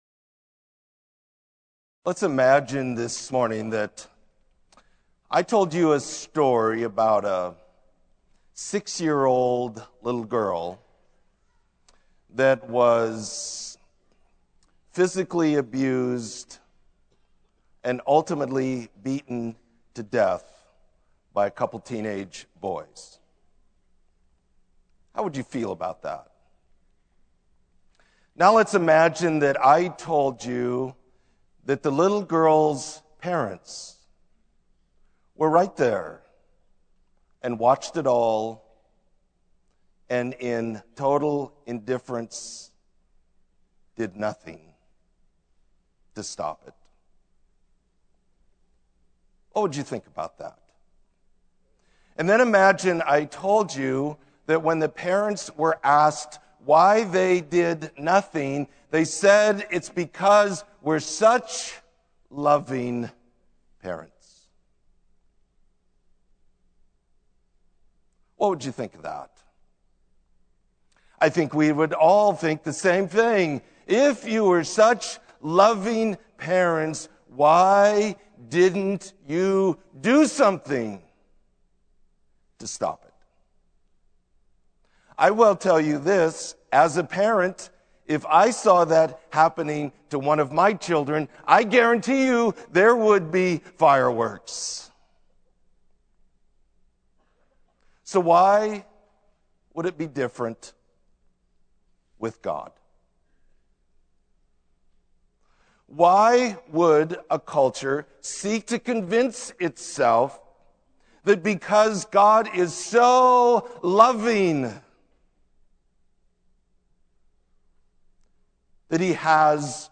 Sermon - Lincoln Berean